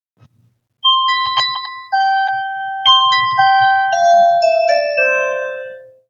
Play Bongo Cat Harp - SoundBoardGuy
Play, download and share Bongo Cat Harp original sound button!!!!
bongo-cat-harp.mp3